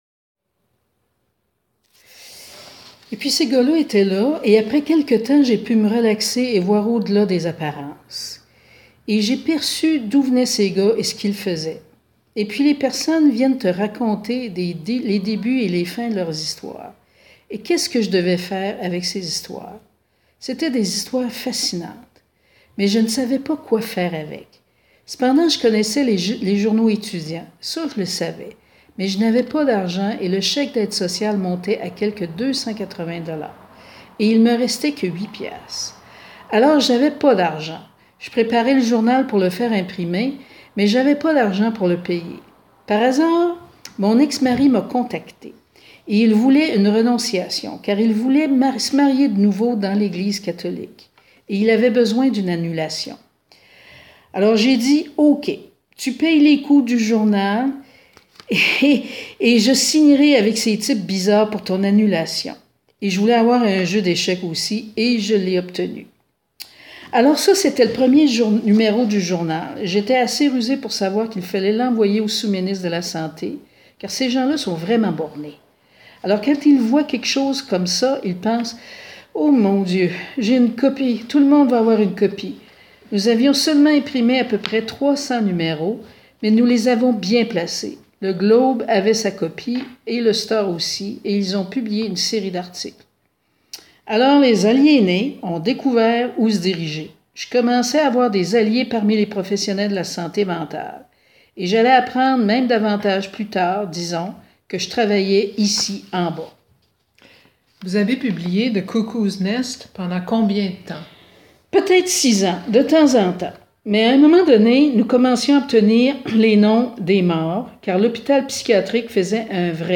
sous forme de segments d’entrevue audio et d’extraits de rédaction.